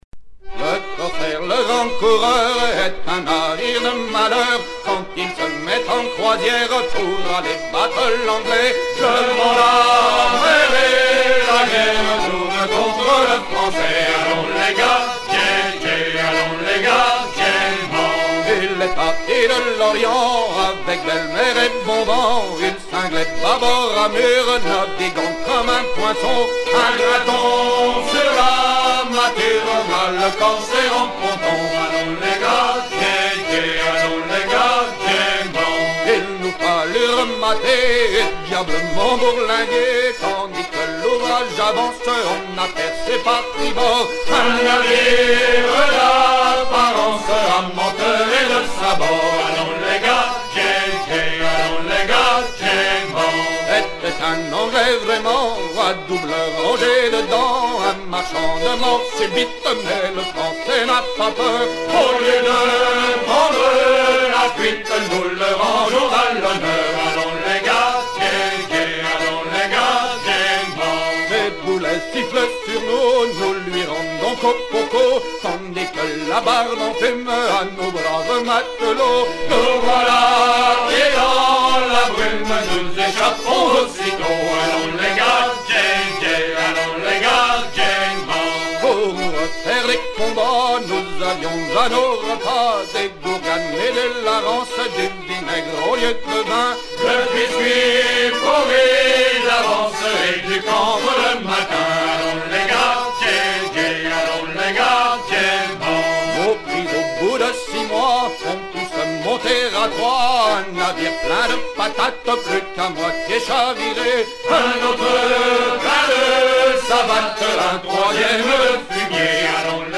à virer au cabestan
Chants de marins
Pièce musicale éditée